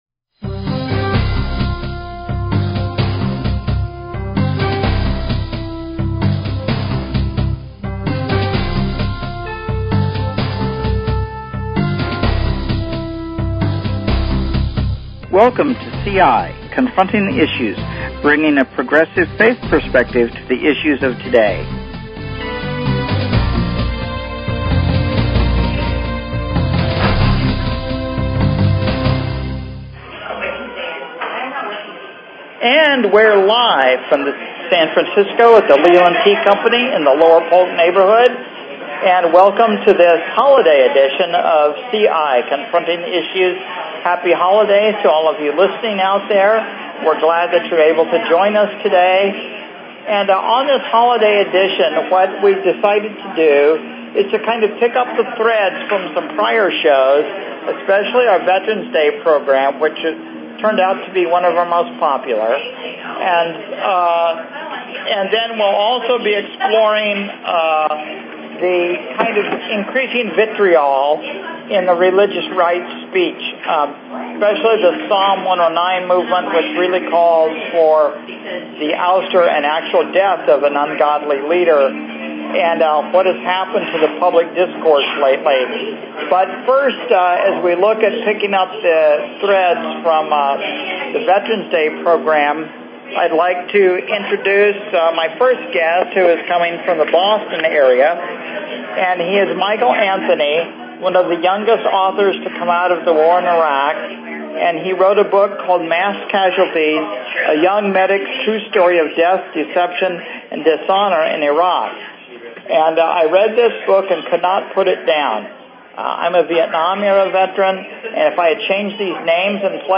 Talk Show Episode, Audio Podcast, CI_Confronting_the_Issues and Courtesy of BBS Radio on , show guests , about , categorized as
Live from the Leland Tea Company, in San Francisco. Bringing a progressive faith perspective to the issues of the day.